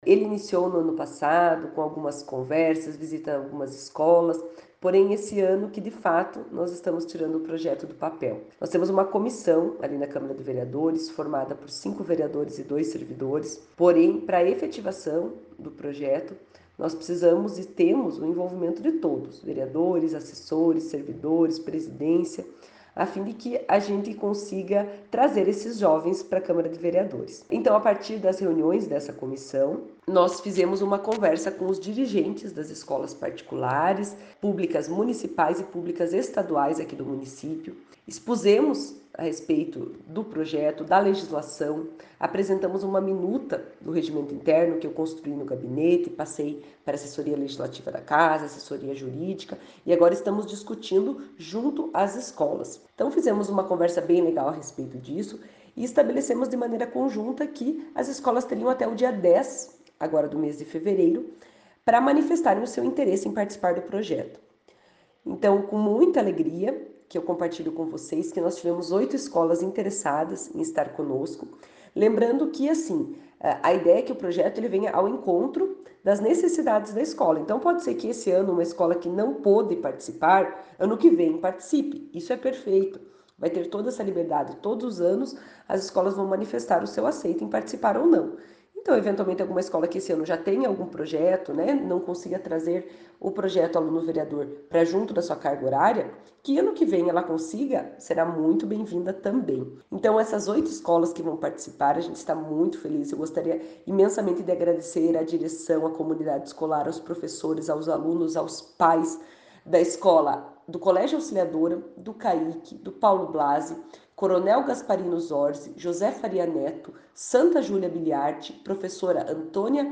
A vereador Celina, enfatiza como será desenvolvido o projeto ao longo do ano.
CELINA.mp3